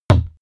Royalty free sounds: Metal